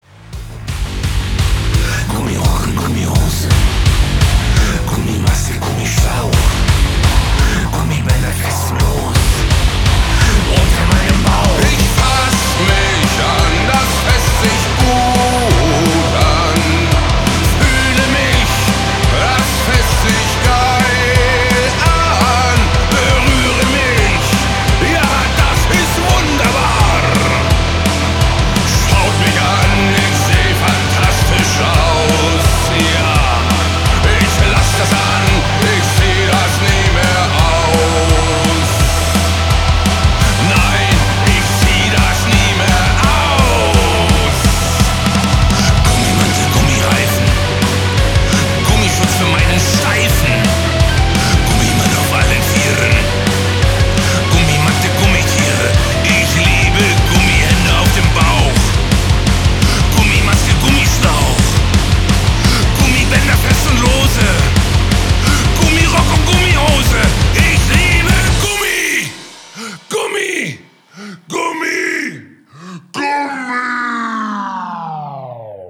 • Качество: 320, Stereo
мощные
Драйвовые
страшные
эпичные
Industrial metal
Neue Deutsche Harte